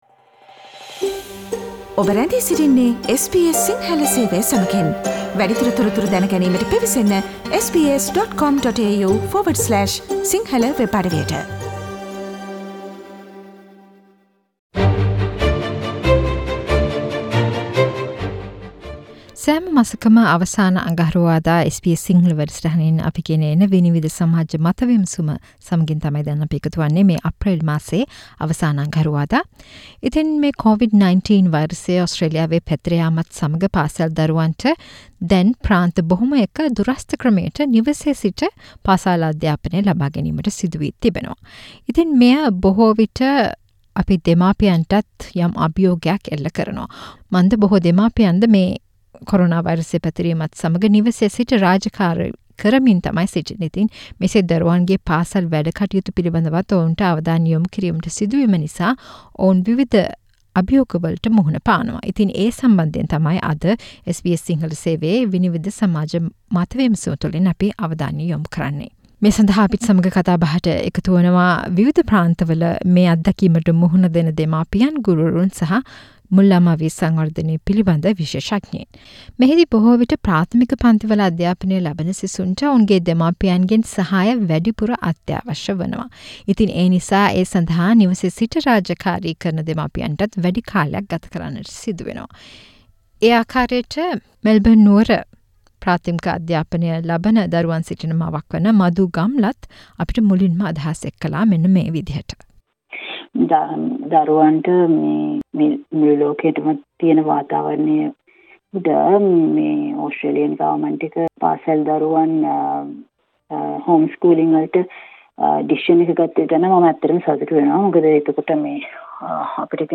This month's SBS Sinhala Vinivida discussion is based on challenges faced by parents when kids learn at home during Covid-19 pandemic.